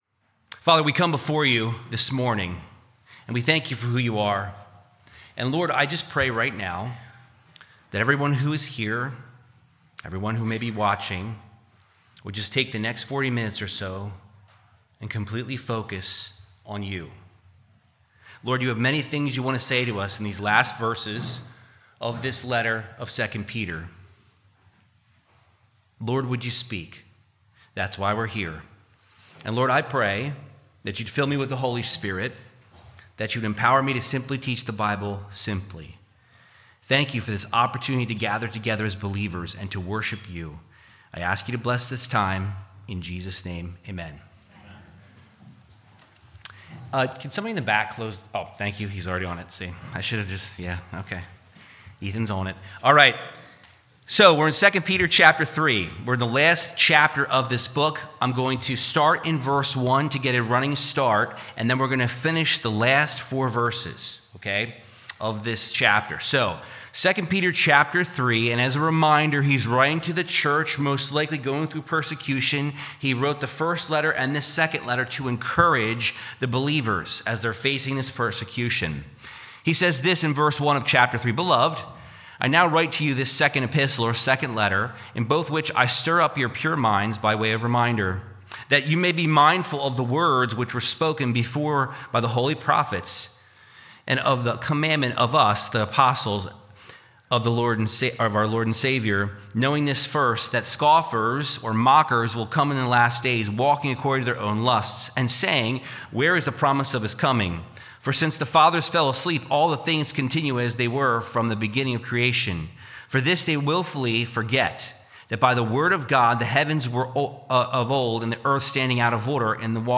Verse by verse teaching 2 Peter 3:14-18